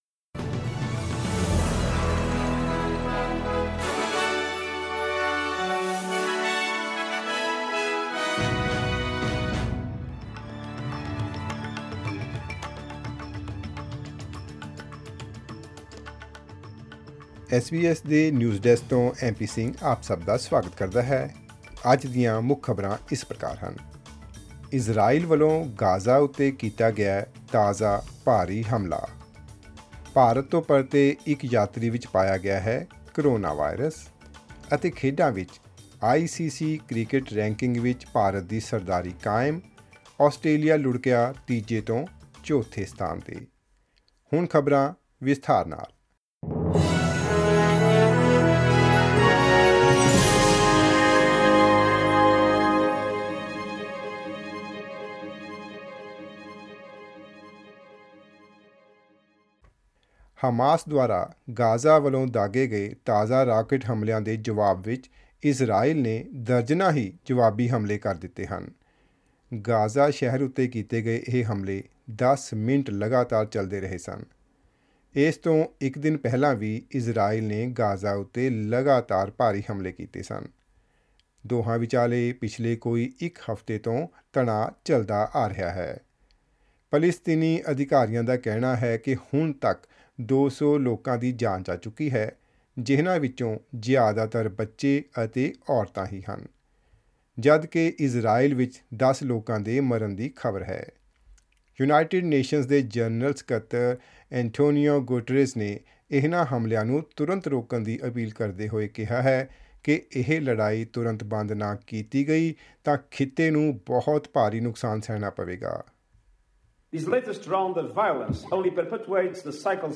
In this bulletin: Israel has launched dozens of more airstrikes on several locations in Gaza in response to rockets from Hamas.